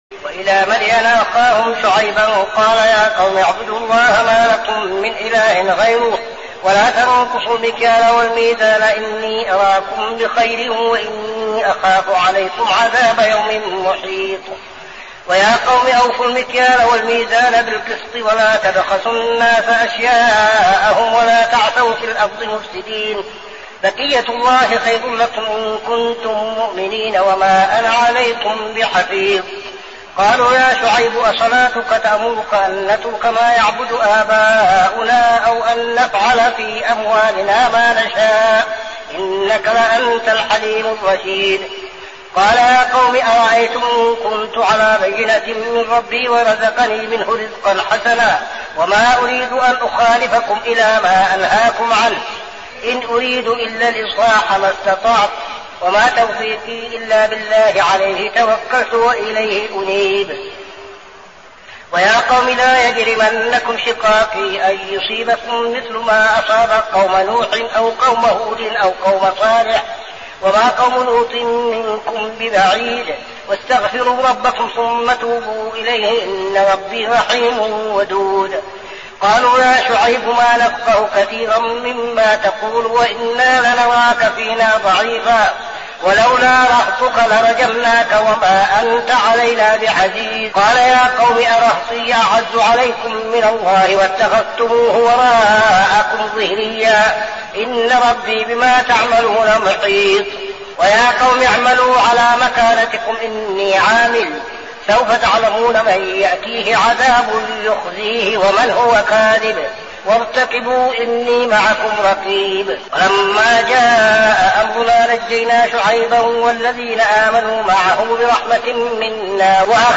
صلاة التراويح عام 1402هـ سورتي هود 84-123 و يوسف 1-49 | Tarawih prayer Surah Hud and Yusuf > تراويح الحرم النبوي عام 1402 🕌 > التراويح - تلاوات الحرمين